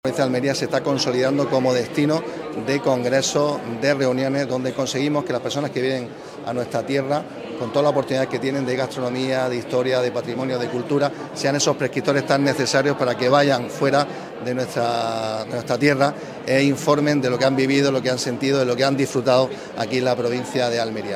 Alcaldesa, delegada del Gobierno y vicepresidente de la Diputación inauguran este encuentro que, bajo el lema ‘Nuestros niños, nuestros pacientes, nuestra razón de ser’, se celebra del 19 al 21 de febrero
ANGEL-ESCOBAR-VICEPRESIDENTE-DIPUTACION.mp3